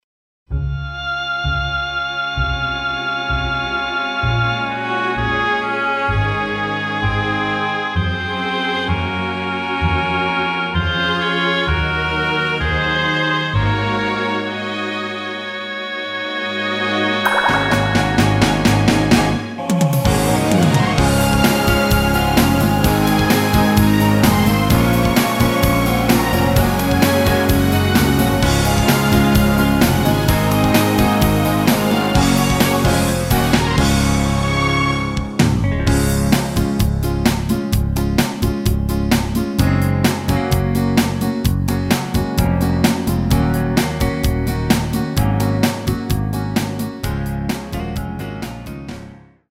MR 입니다.
원곡의 보컬 목소리를 MR에 약하게 넣어서 제작한 MR이며